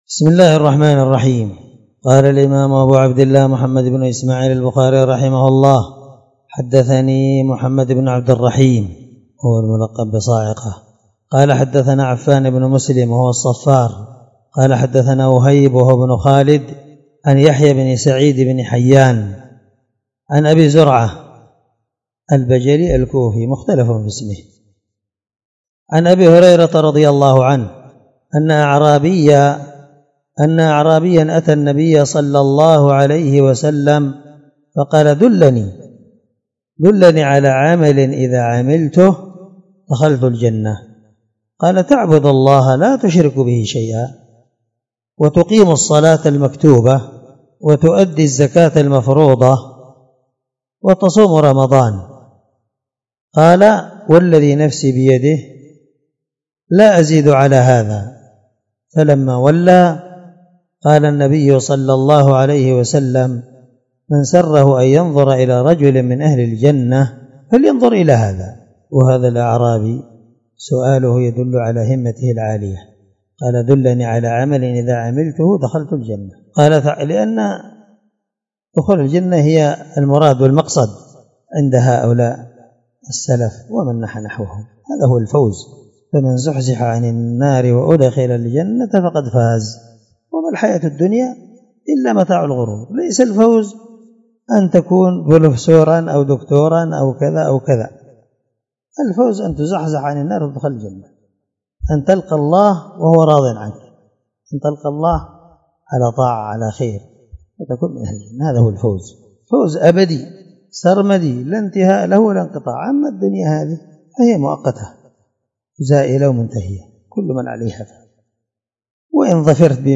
الدرس 2من شرح كتاب الزكاة حديث رقم(1397 )من صحيح البخاري